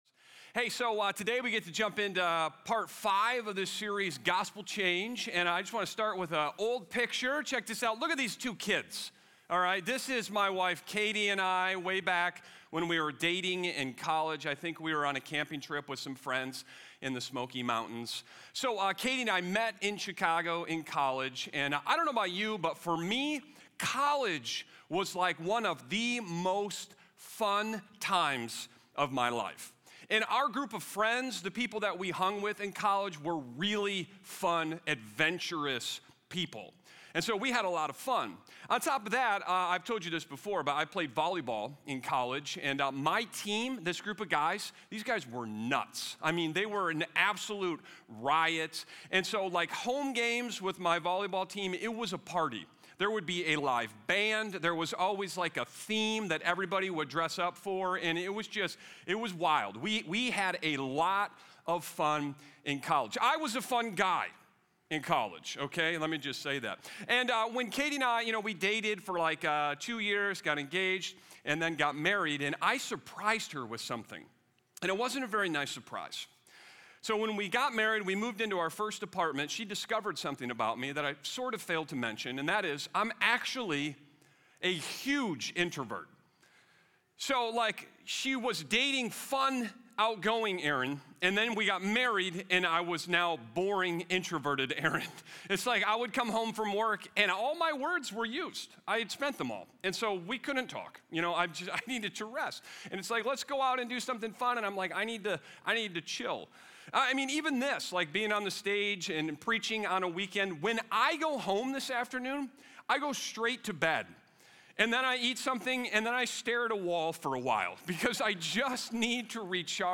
1-4 Show Resources Resources Beyond the Weekend Sermon Discussion Download Audio Listen on Spotify Itunes Audio Podcast Ephesians 6